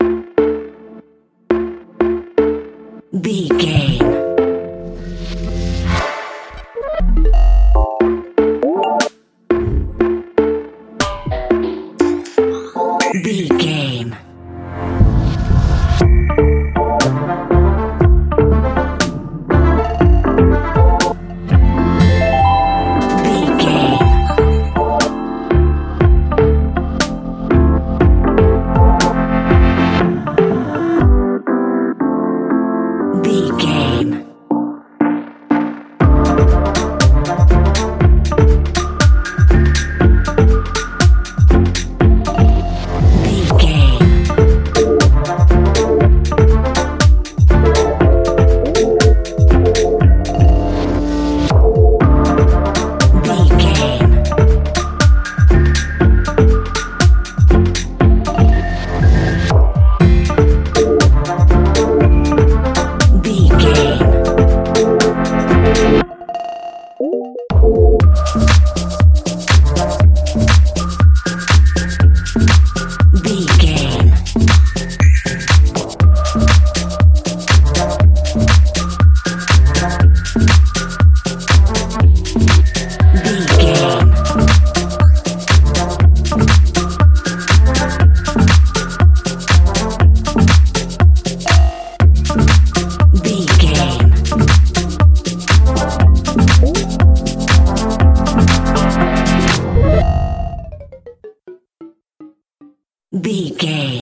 Aeolian/Minor
WHAT’S THE TEMPO OF THE CLIP?
futuristic
industrial
hopeful
synthesiser
drum machine